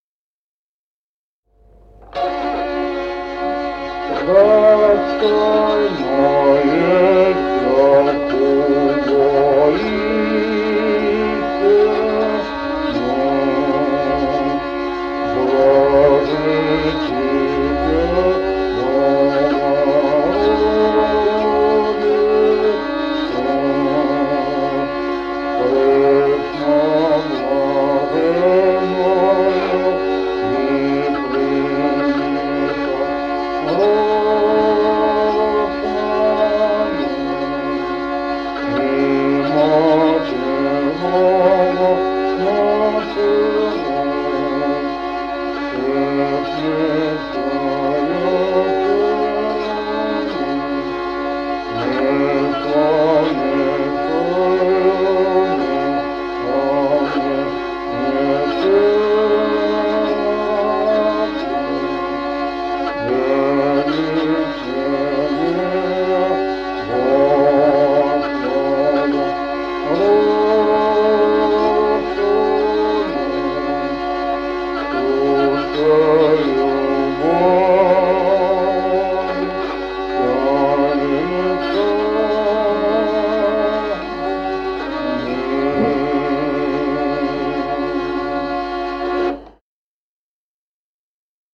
Музыкальный фольклор села Мишковка «Достойно есть», песнопение Пресвятой Богородице.